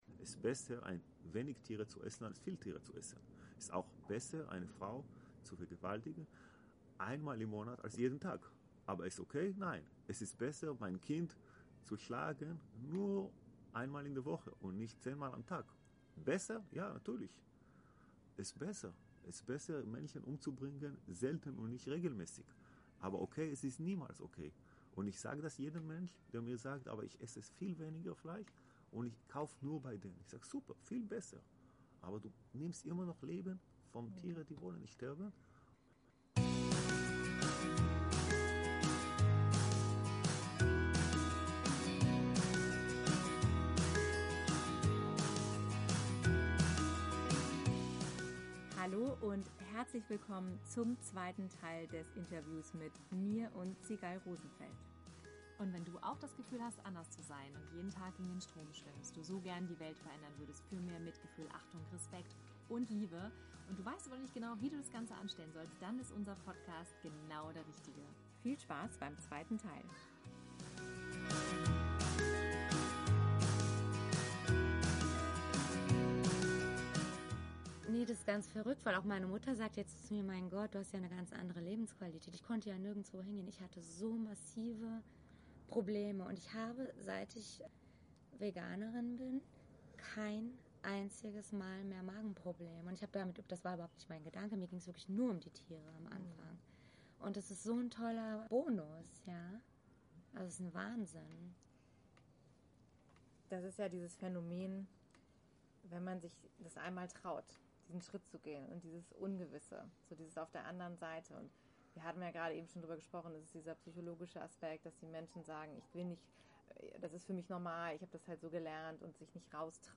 92 Liebe auf dem Teller - Interview Special